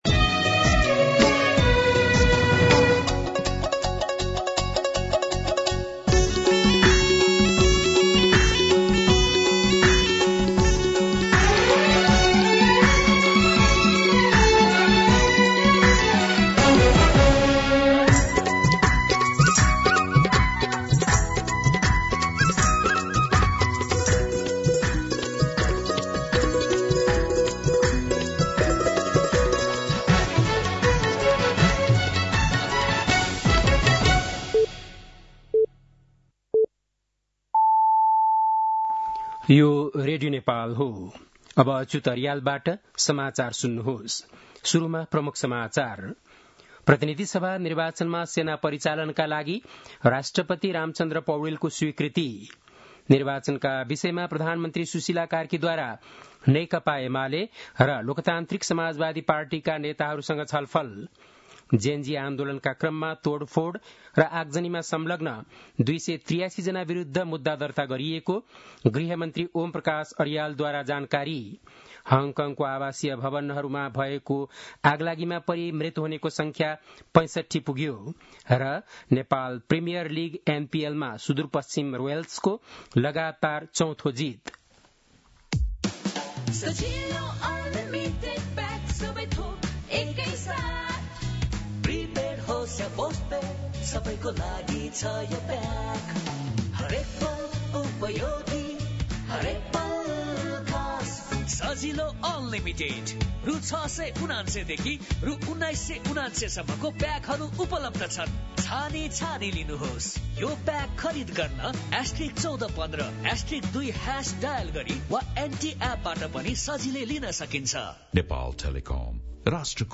बेलुकी ७ बजेको नेपाली समाचार : ११ मंसिर , २०८२
7-pm-nepali-news-8-11.mp3